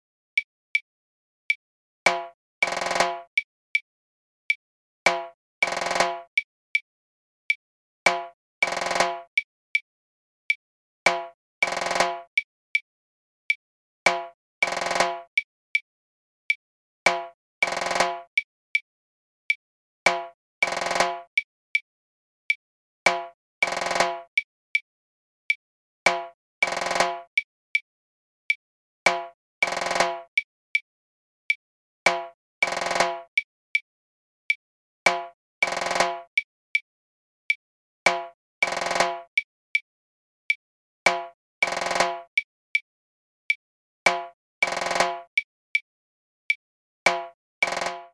Il est composé d'un coup accentué suivi d'un roulement de 7 ou 9 coups en fonction de la vitesse de la musique :
Abanico sur une clave 2/3
danzon_timbales_abanico.mp3